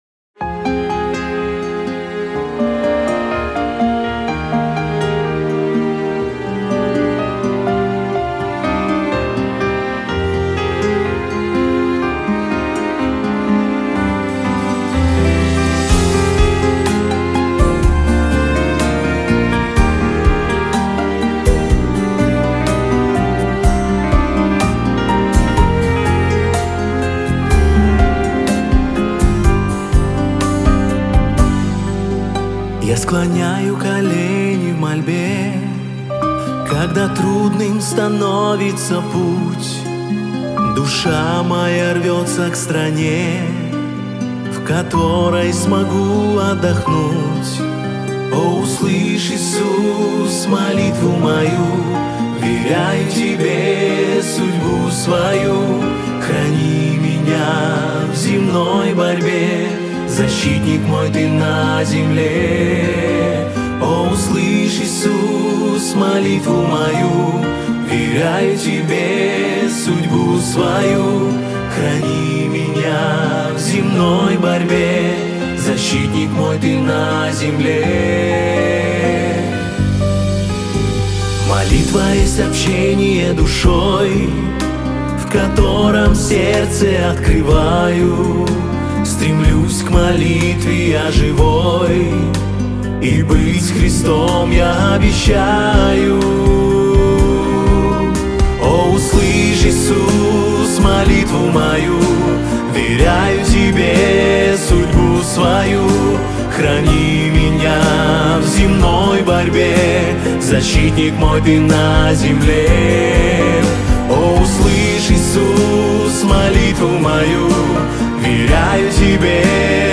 3648 просмотров 3302 прослушивания 593 скачивания BPM: 124